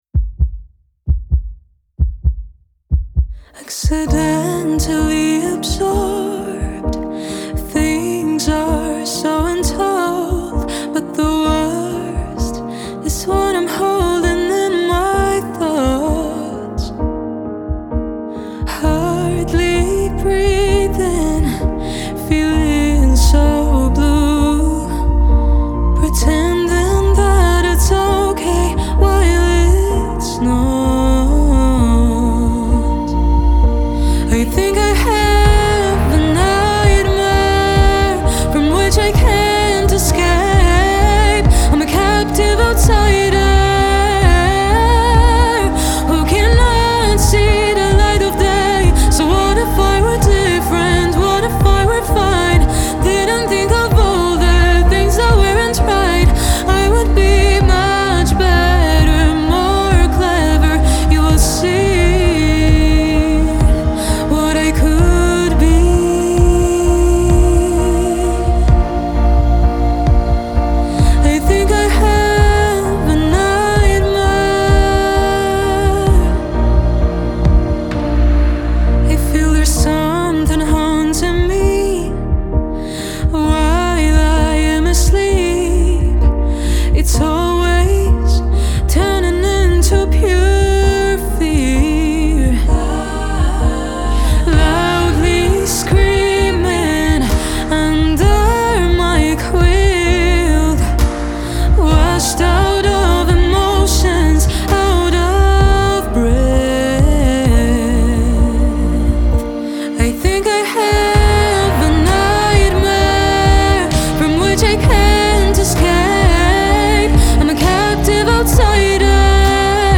ballada w umiaekowanym tempie emocjonalna